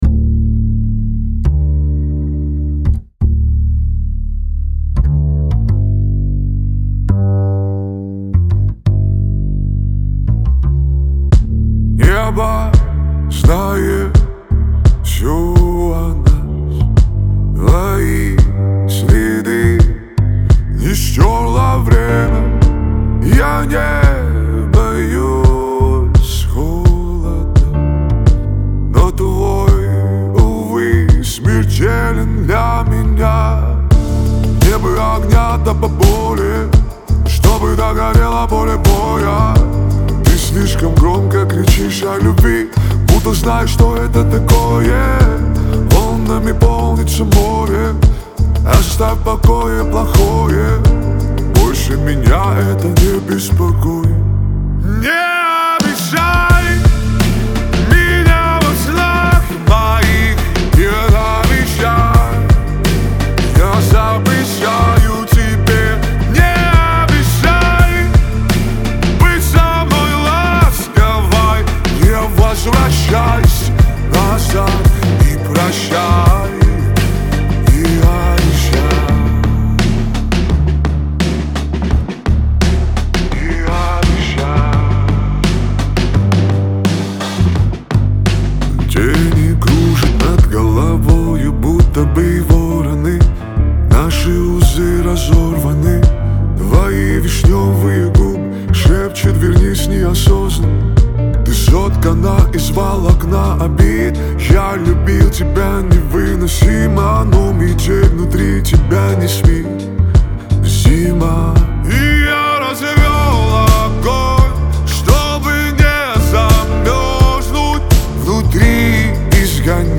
Лирика , Кавказ поп , грусть